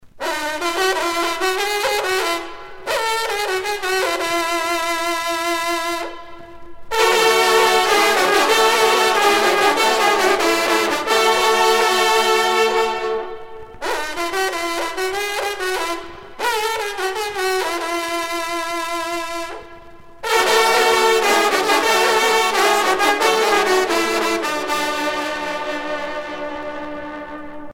trompe - fanfare
circonstance : vénerie